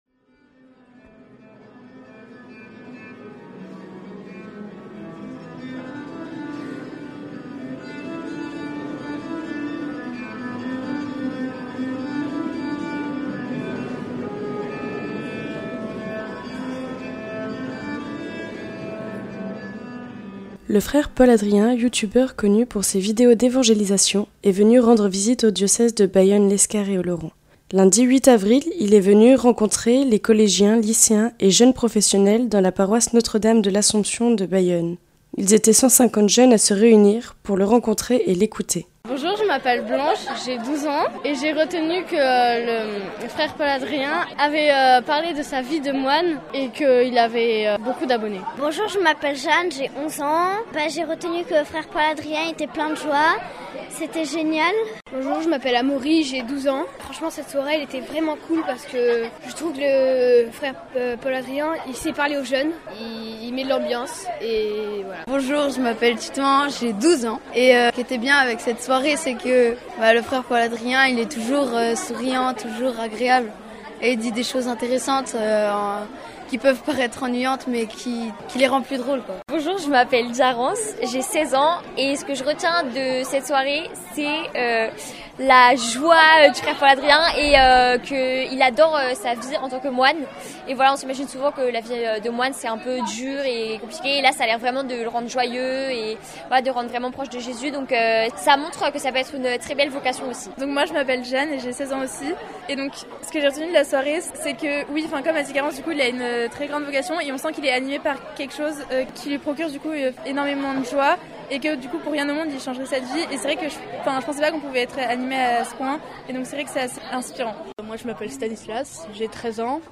Témoignages recueillis le 8 avril 2024 lors de la soirée proposée par la paroisse de Bayonne Centre à Saint-Amand.